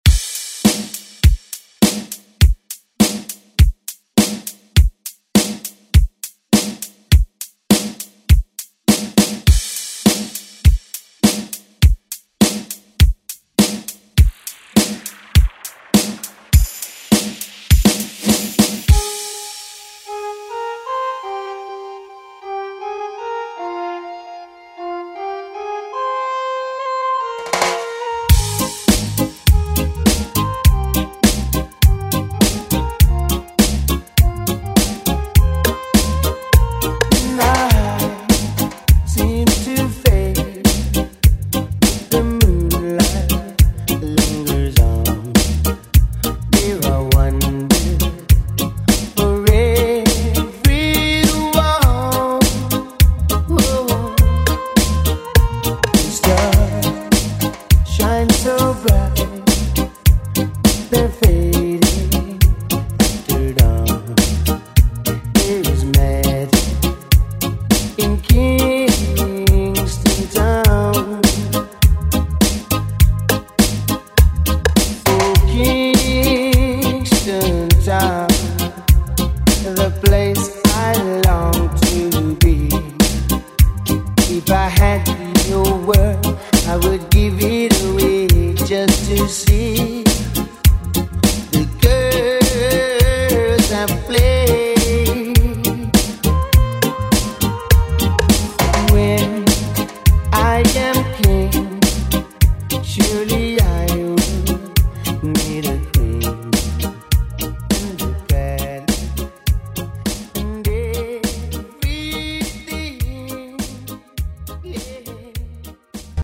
Genre: 80's
Clean BPM: 87 Time